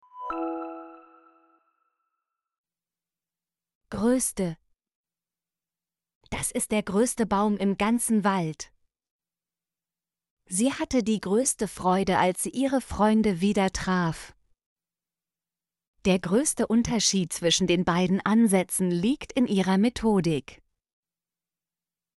größte - Example Sentences & Pronunciation, German Frequency List